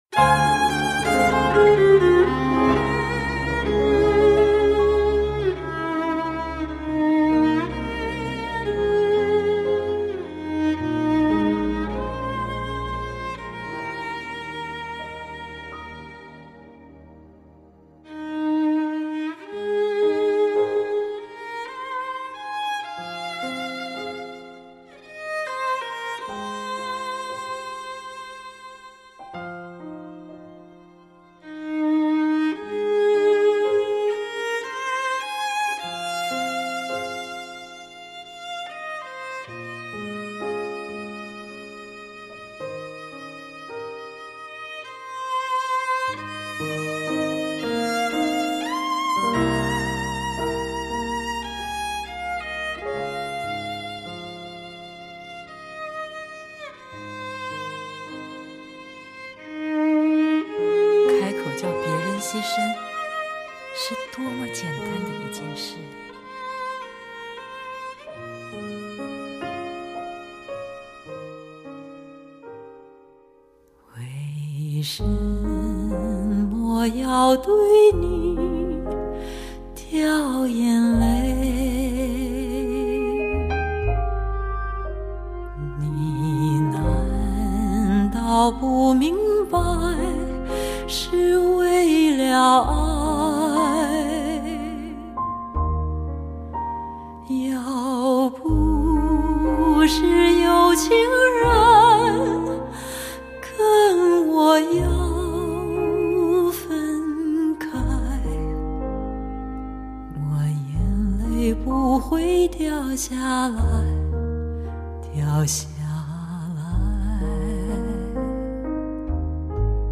她波澜不惊、低回委婉的歌声，是一种被遗忘了的古老语言，有着一种古典的浪漫，一种优雅的感伤。